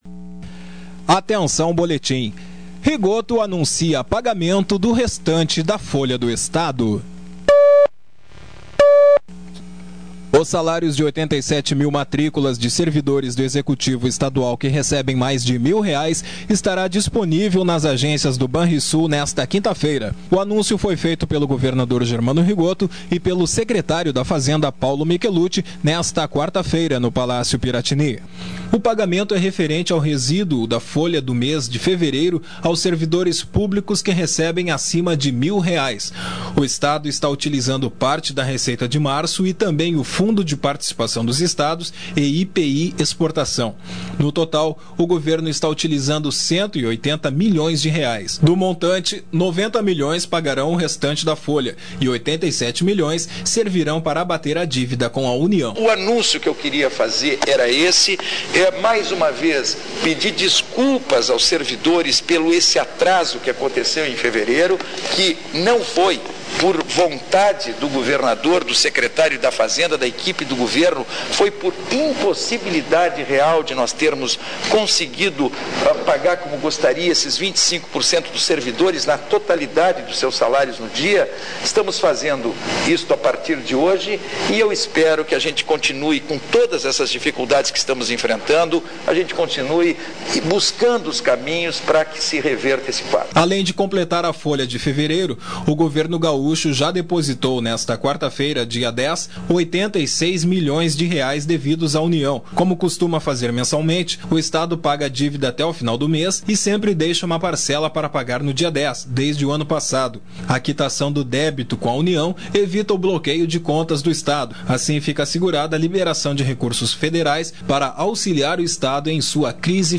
O salário de 87 mil matrículas de servidores do Executivo estadual que recebem mais de R$ 1 mil estará disponível nas agências do Banrisul nesta 5ª feira. Sonora: governador Germano Rigotto.